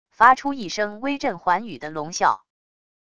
发出一声威震寰宇的龙啸wav音频